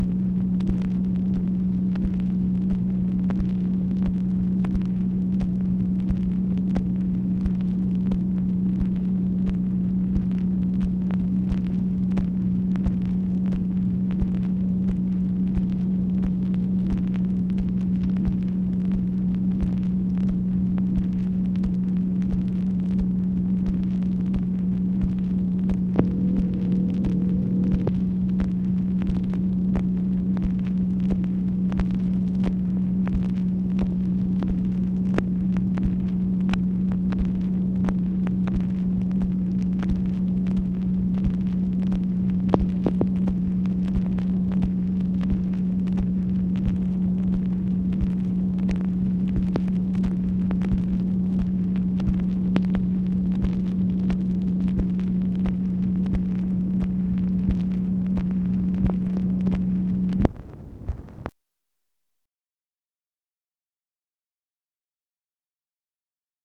MACHINE NOISE, January 19, 1966